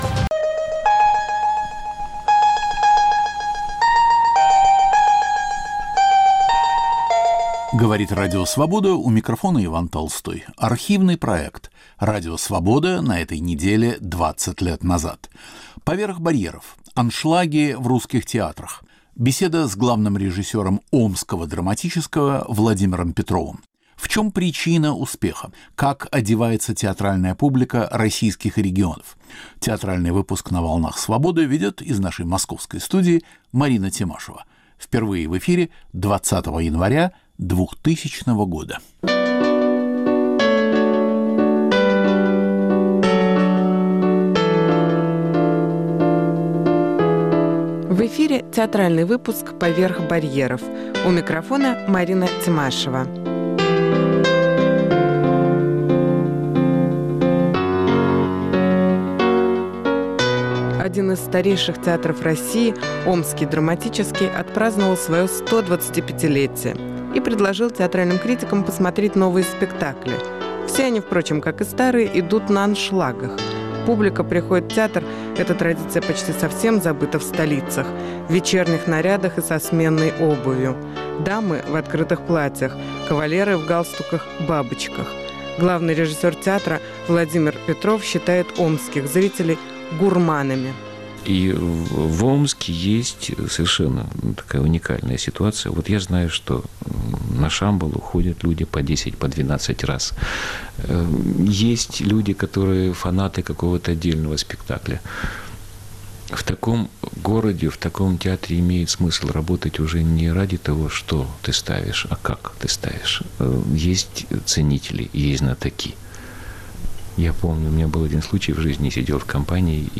Радио Свобода на этой неделе 20 лет назад. Рассказывает дочь генерала Деникина